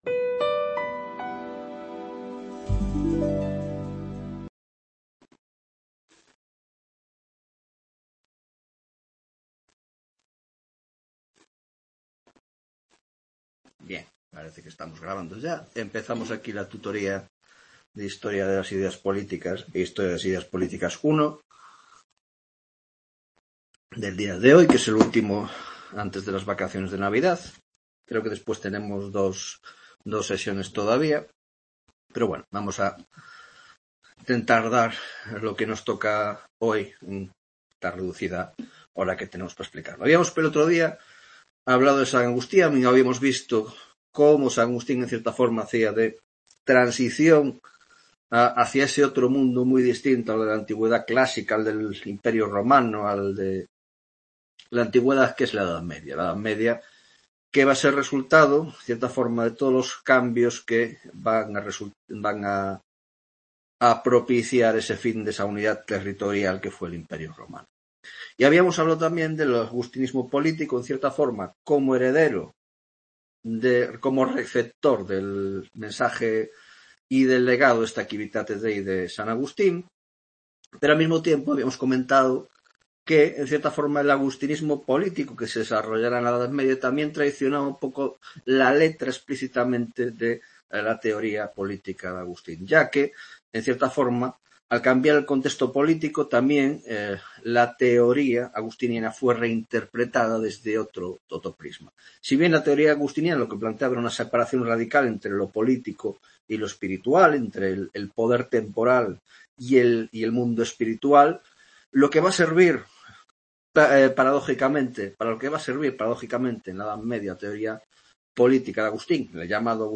9ª Tutoria de Historia de las Ideas Políticas (Grado de Ciéncias Políticas y Grado de Sociologia)